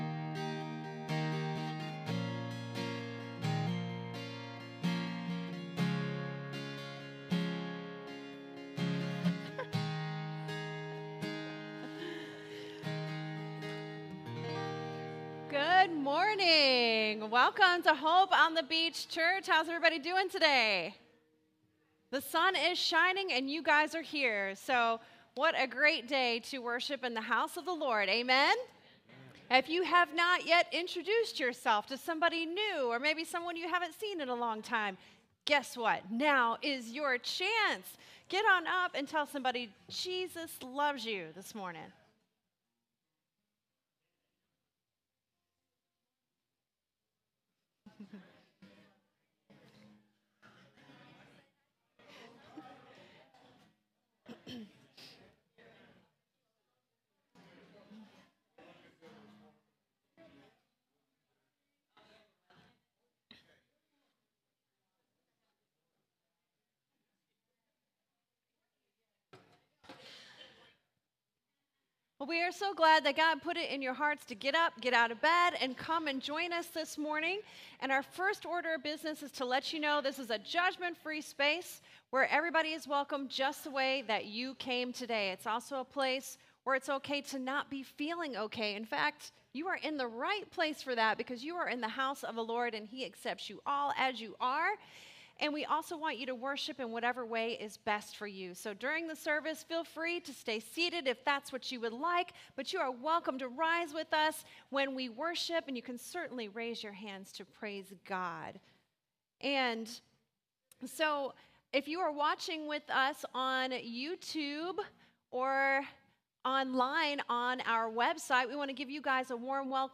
SERMON DESCRIPTION The Christian faith is a gift of God’s grace and yet we often add to it and confuse it.